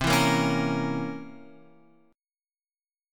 C 7th Flat 9th